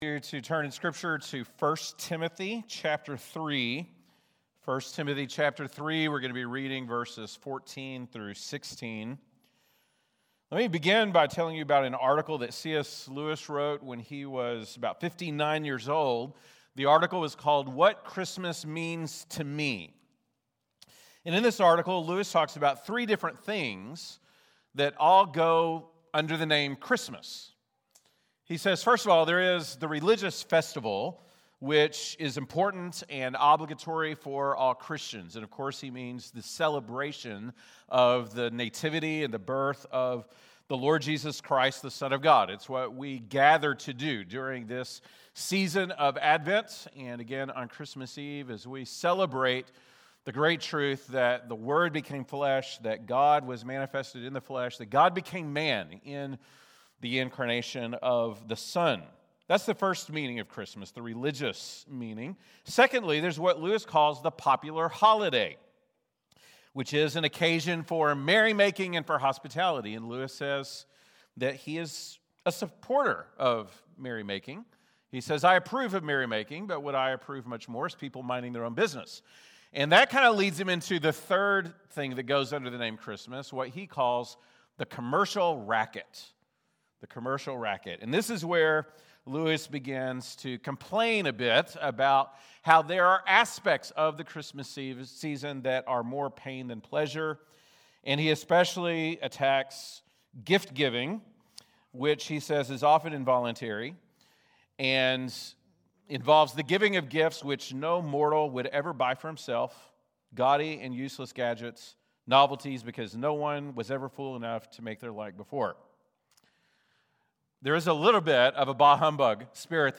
December 21, 2025 (Sunday Morning)